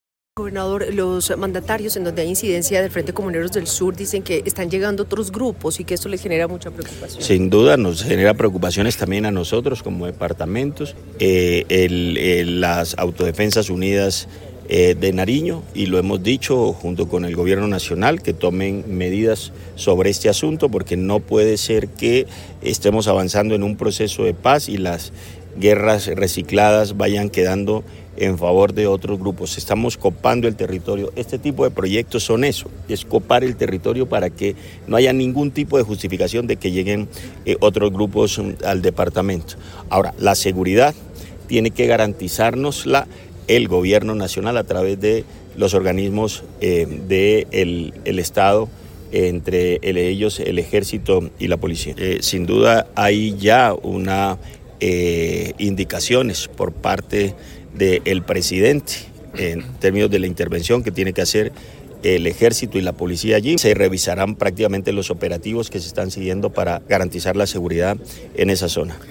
Gobernador de Nariño-AUN